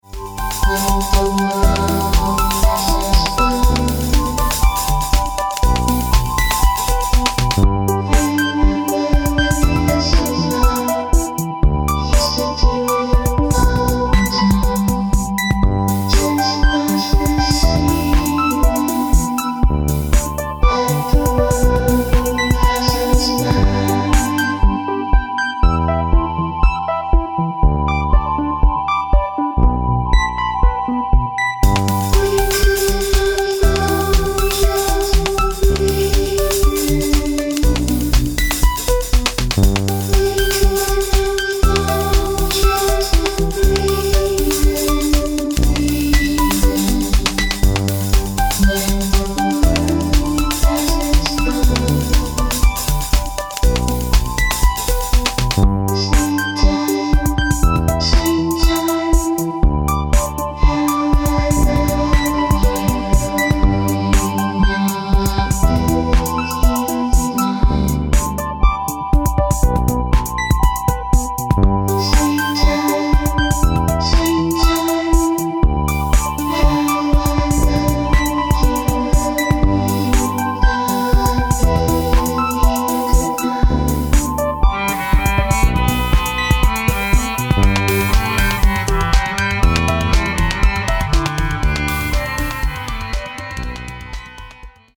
vocals
concertina